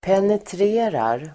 Uttal: [penetr'e:rar]